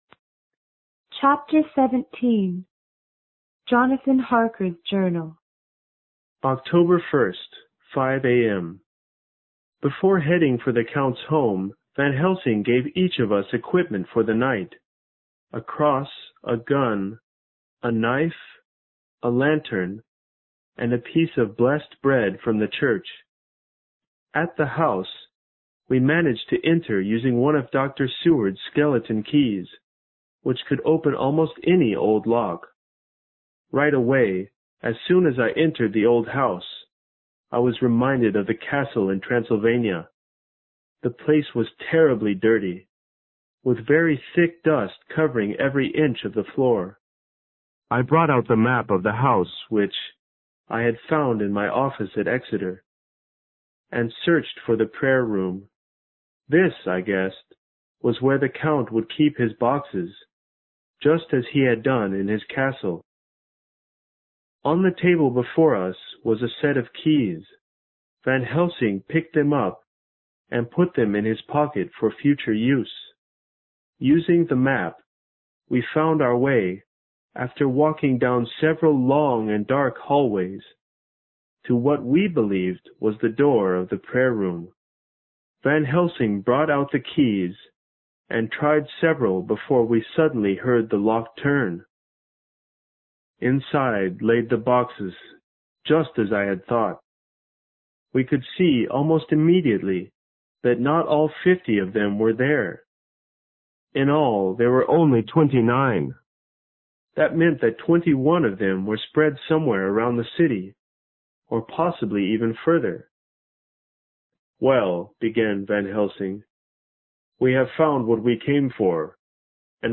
在线英语听力室有声名著之吸血鬼 Chapter17的听力文件下载,有声名著之吸血鬼－在线英语听力室